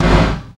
KIK XR.BDR03.wav